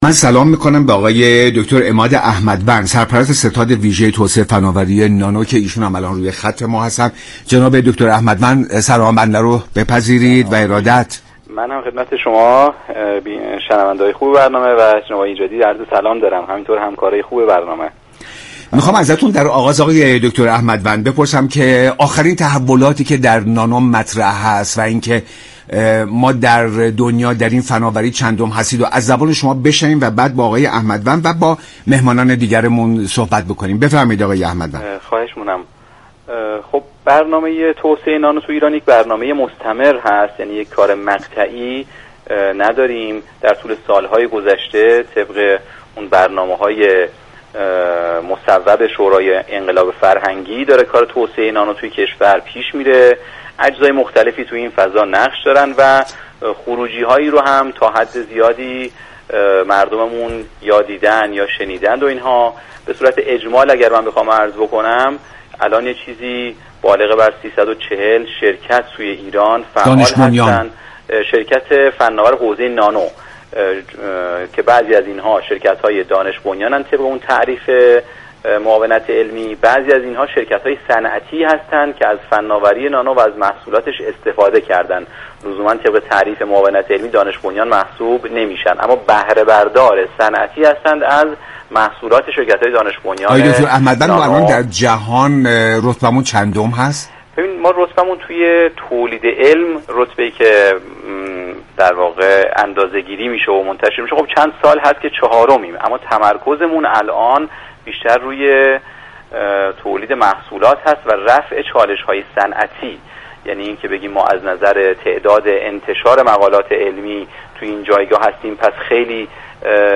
رویداد
دبیر ستاد ویژه توسعه نانو گفت: در حال حاضر تمركز را بر تولید محصولات و رفع چالش های صنعتی گذاشته ایم.